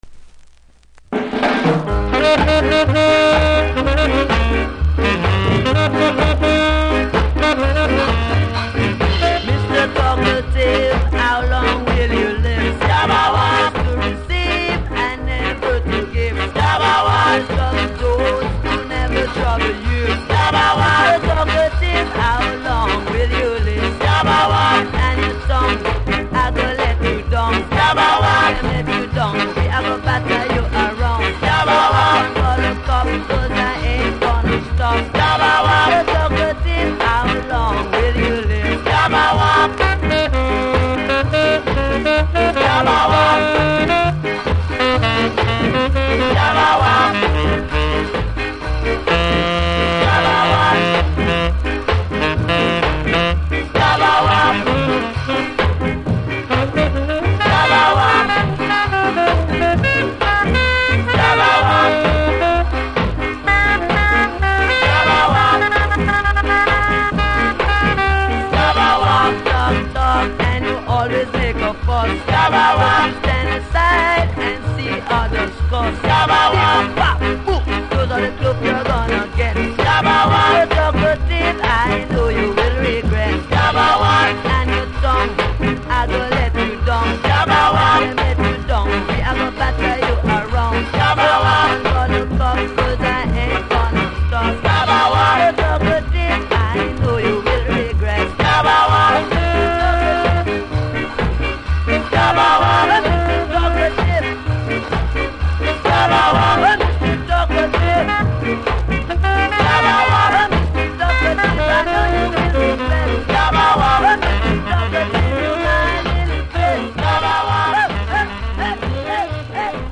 多数キズありで見た目悪いですが音にはそれほど影響せずプレイ可レベルですので試聴で確認下さい。